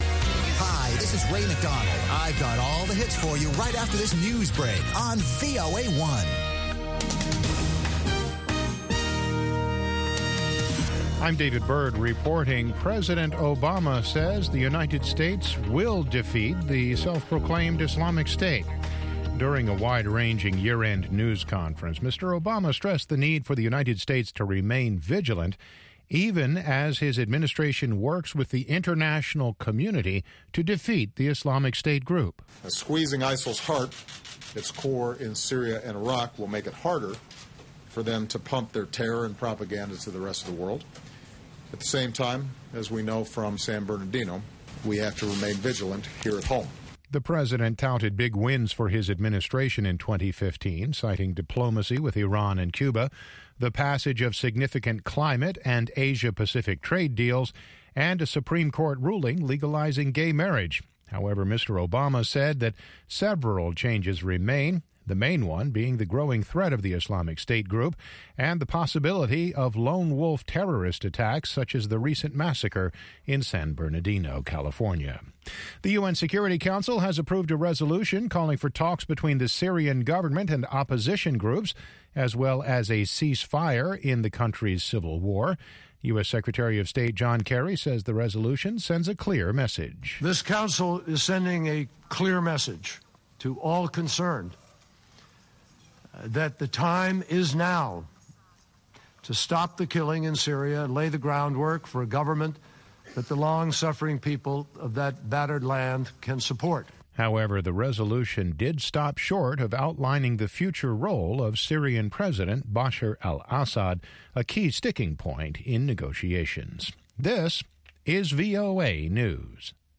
اخبار